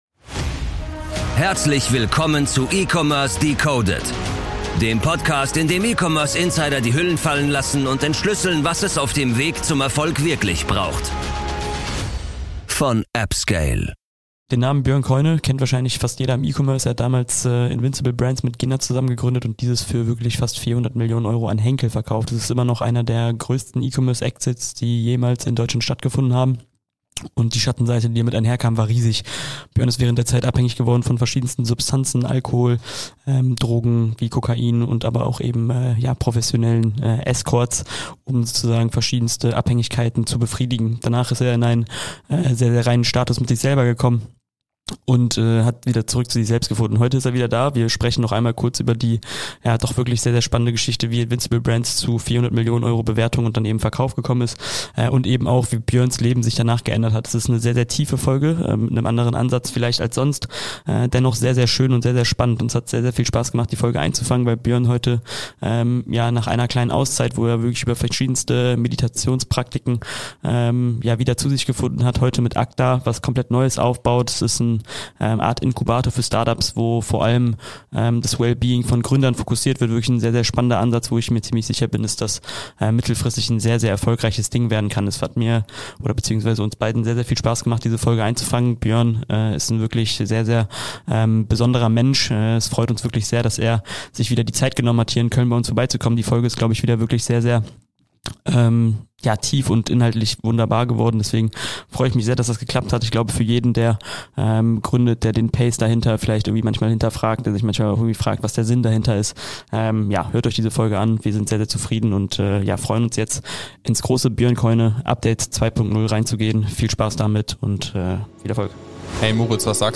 Wir freuen uns immer wieder, dich im Studio zu haben.